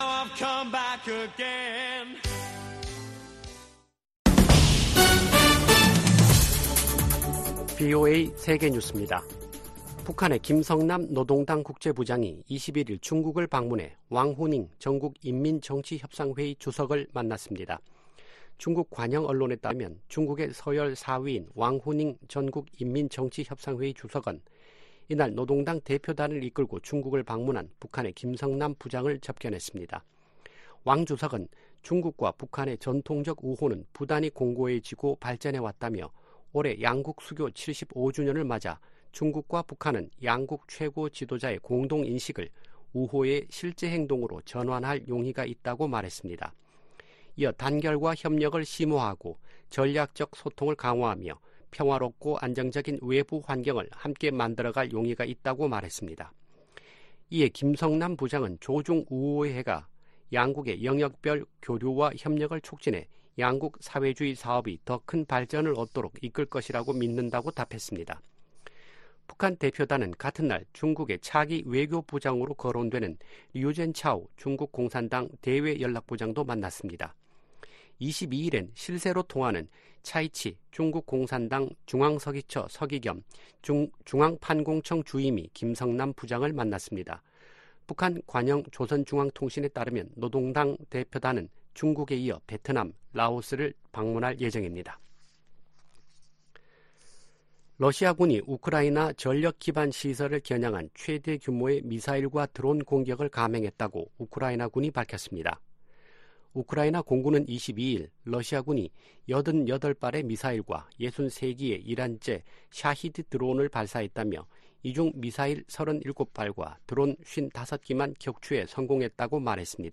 VOA 한국어 아침 뉴스 프로그램 '워싱턴 뉴스 광장' 2024년 3월 23일 방송입니다. 미국-한국 전시작전권 전환 작업이 완료를 위한 궤도에 있다고 폴 러캐머라 주한미군사령관이 밝혔습니다. 북한이 대륙간탄도미사일(ICBM)로 핵탄두를 미 전역에 운반할 능력을 갖췄을 것이라고 그레고리 기요 미 북부사령관 겸 북미 항공우주방위사령관이 평가했습니다. 윤석열 한국 대통령은 ‘서해 수호의 날'을 맞아, 북한이 도발하면 더 큰 대가를 치를 것이라고 경고했습니다.